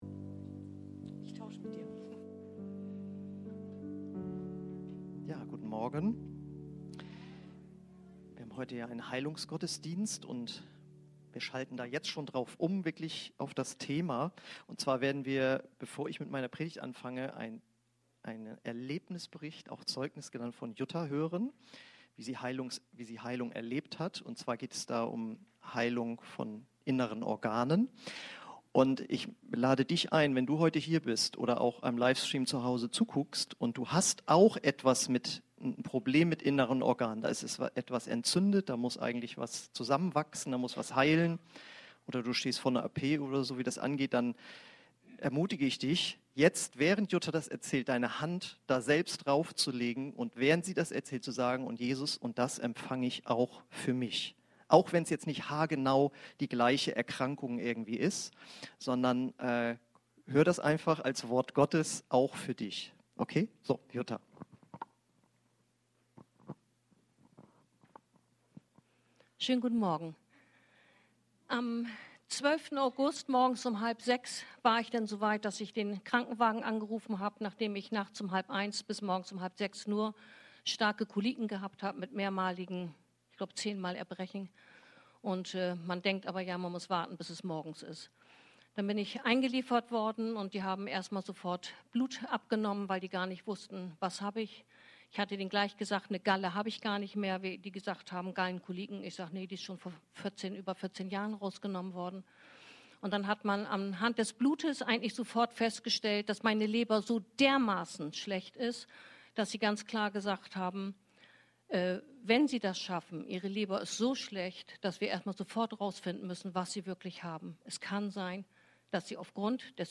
Aus der Predigtreihe: "Die erweckte Kirche..."